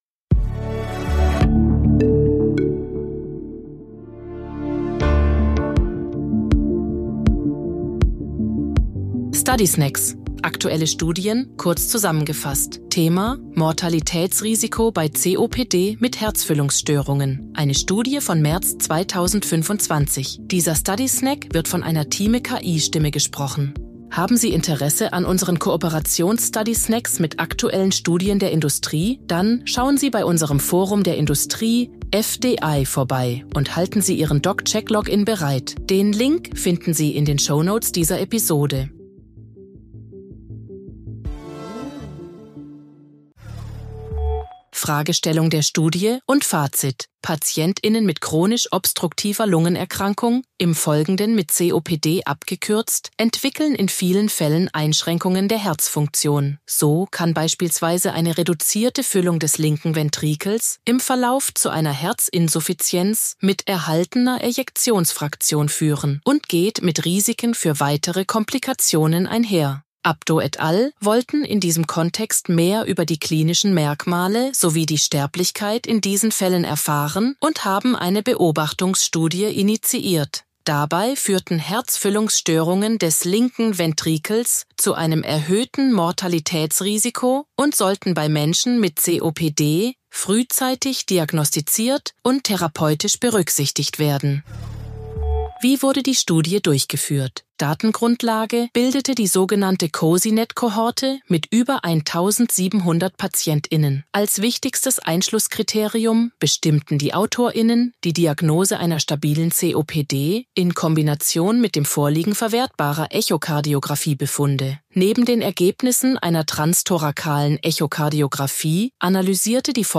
Hilfe von künstlicher Intelligenz (KI) oder maschineller